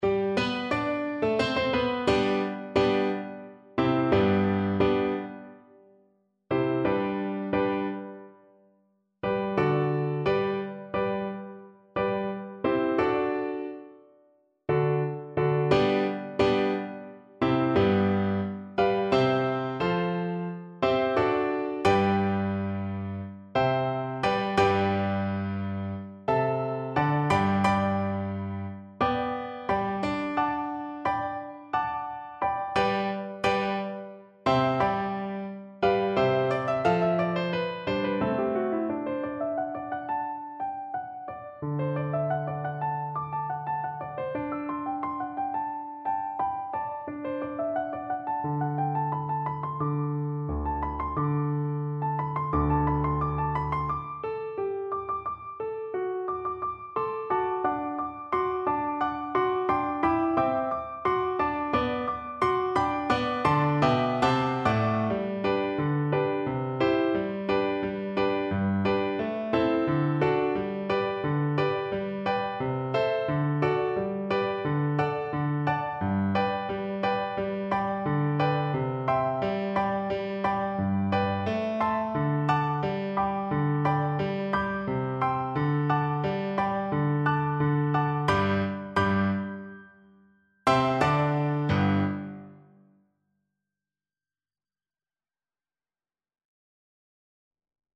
Viola
G major (Sounding Pitch) (View more G major Music for Viola )
Moderately = c. 88
2/4 (View more 2/4 Music)
D4-G5
Traditional (View more Traditional Viola Music)